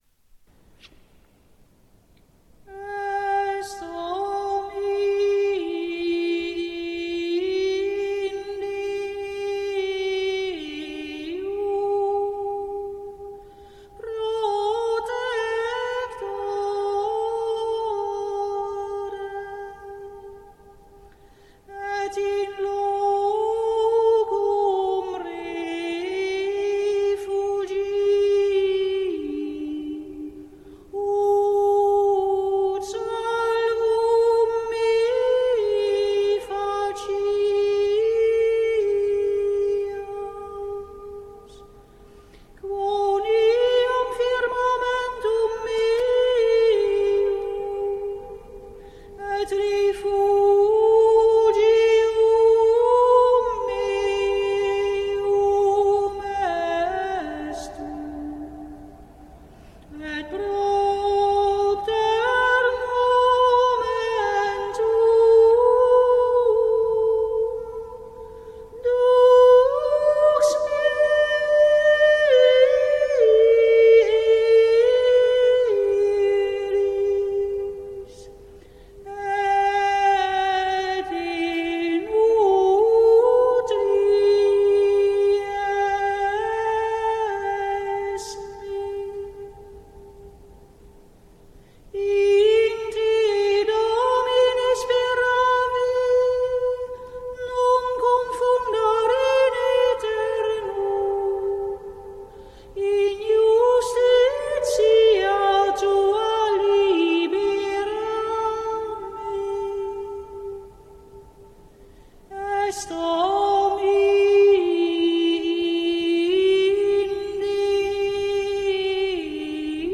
Esto mihi (Ps. 30, 3-4), introitus  WMP   RealPlayer